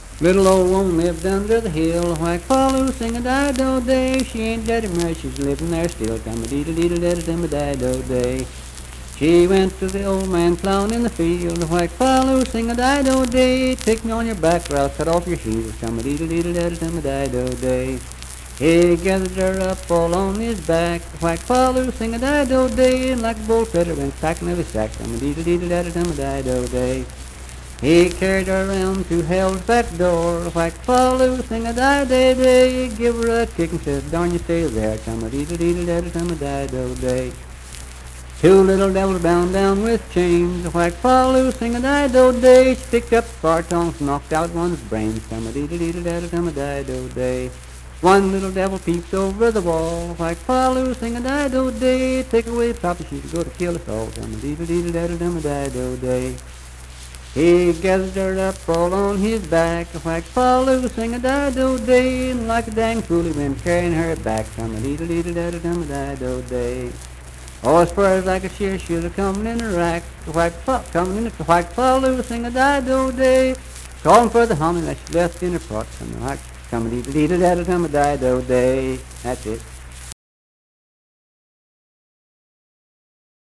Unaccompanied vocal music performance
Verse-refrain 8(4w/R).
Voice (sung)